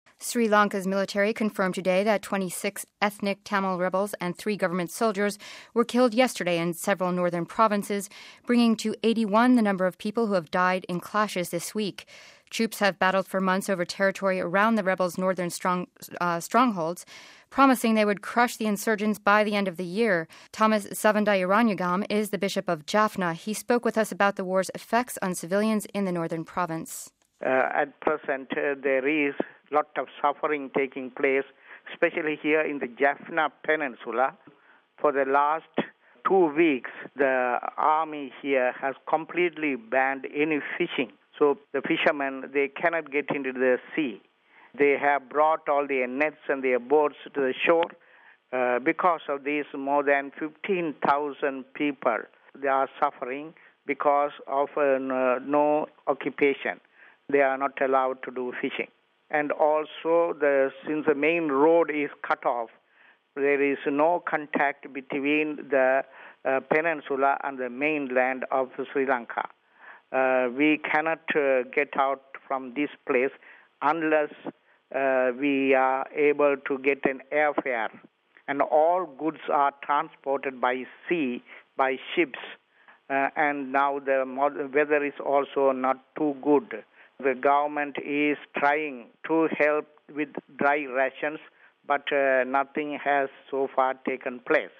Thomas Savundaranayagam is the Bishop of Jaffna. He spoke with us about the war’s effects on civilians in the northern province.